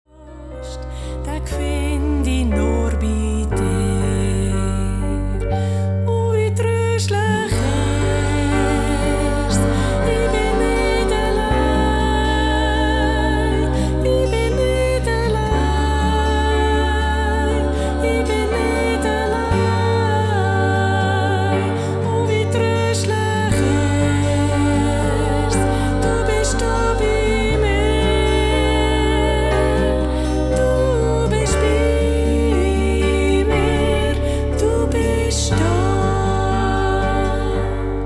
Ihre sanften Lieder in 432Hz
verbreiten eine Atmosphäre von Frieden und Zuversicht